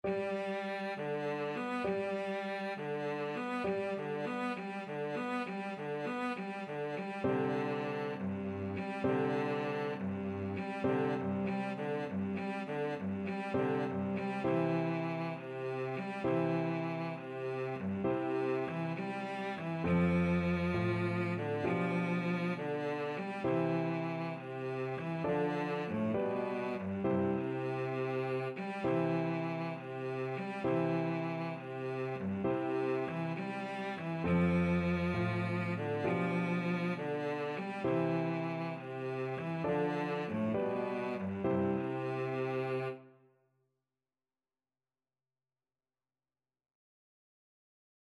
Allegretto
6/8 (View more 6/8 Music)
Classical (View more Classical Cello Music)